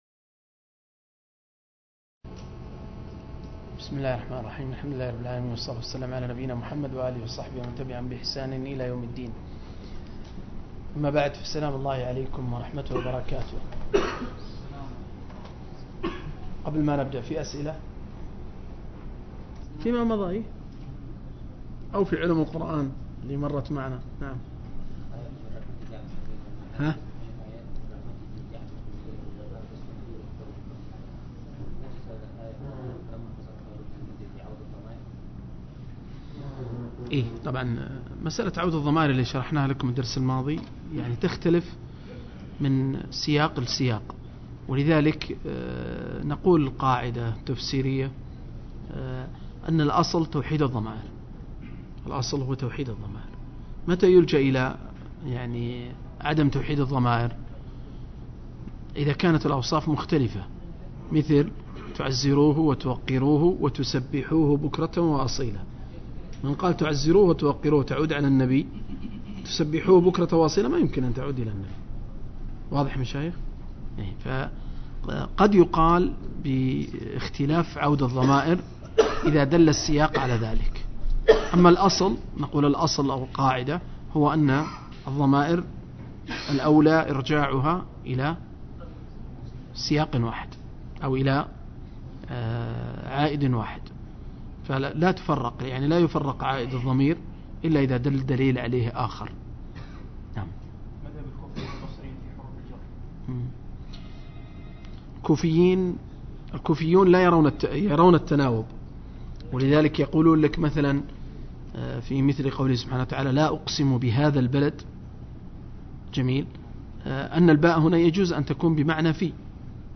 052- عمدة التفسير عن الحافظ ابن كثير – قراءة وتعليق – تفسير سورة البقرة (الآيتين 254-253)